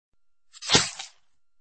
knife-stab-jason-sound-effect.mp3